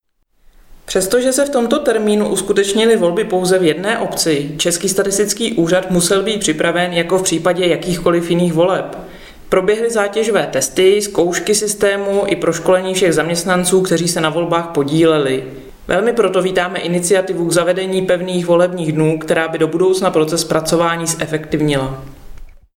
Vyjádření Evy Krumpové, místopředsedkyně ČSÚ, soubor ve formátu MP3, 891.88 kB